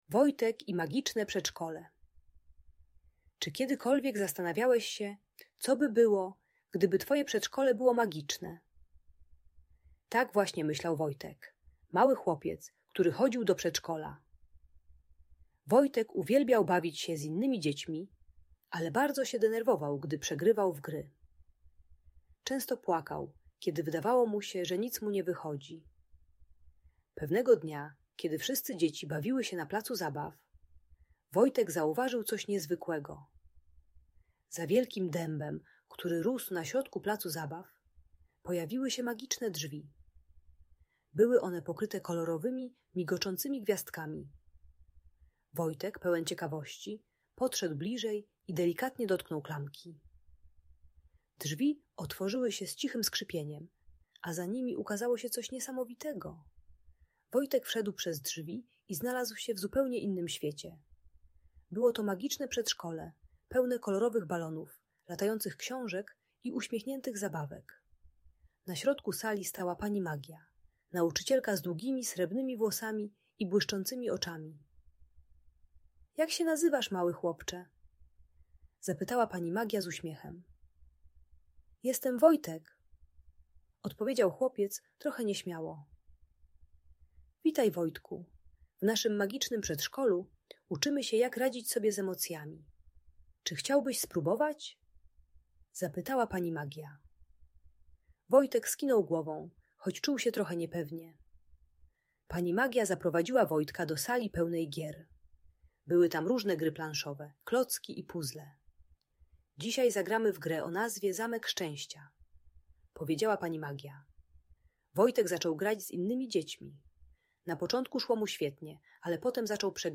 Opowieść o Wojtku i Magicznym Przedszkolu - Lęk wycofanie | Audiobajka